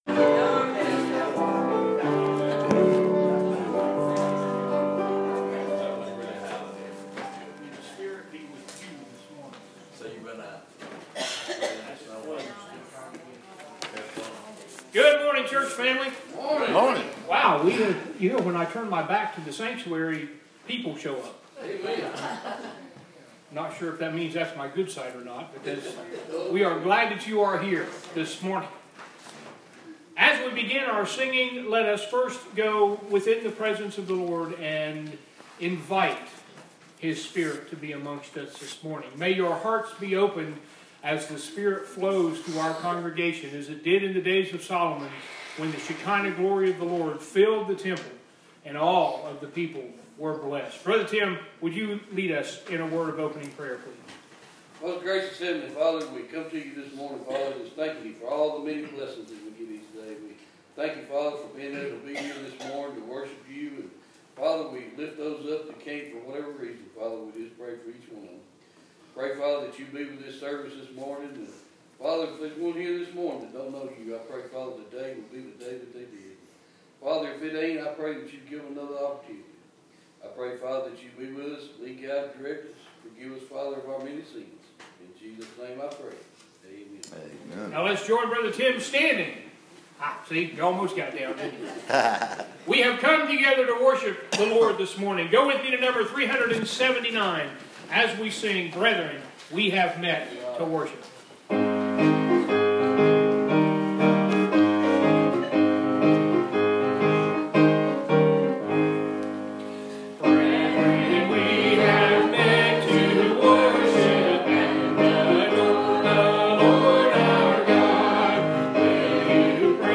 Purpose Of Sermon: To Make Us Aware That God Is Watching Us, And Blesses Those Who Live In Him Through Christ.